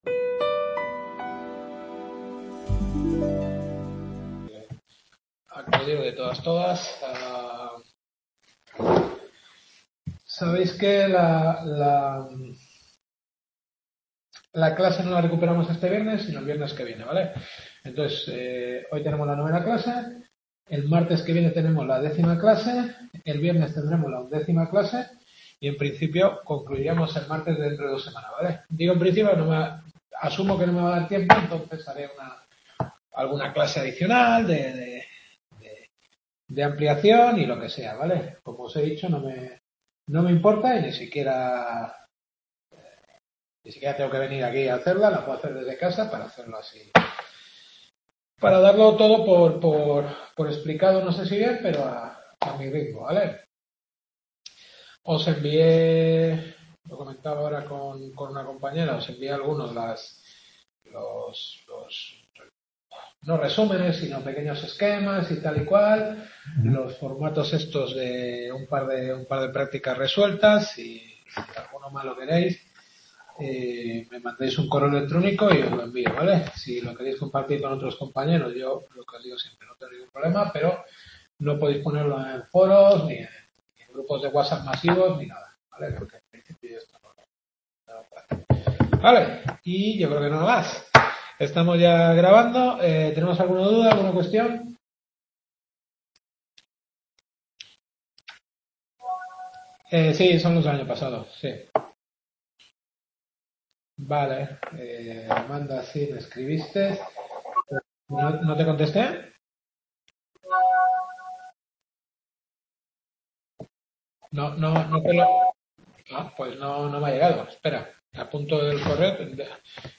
Cultura Europea en España. Novena Clase.